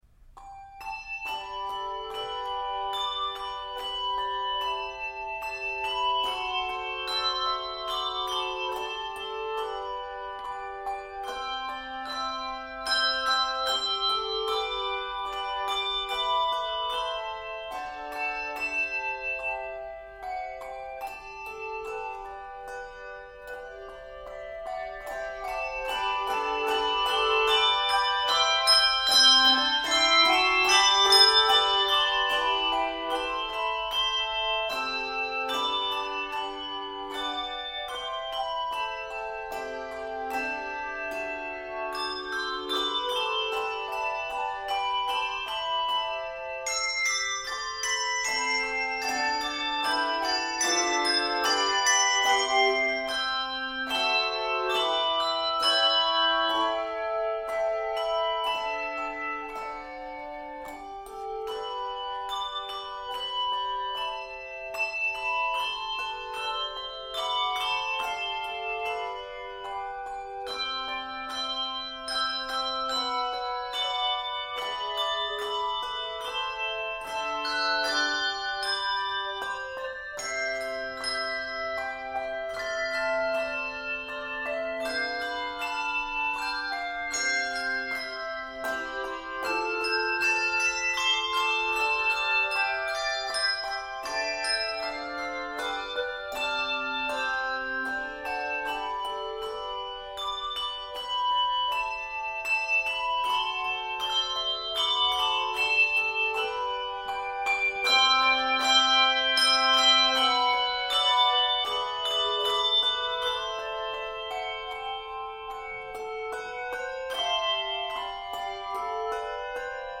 Key of G Major. 58 measures.